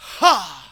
VOX SHORTS-1 0003.wav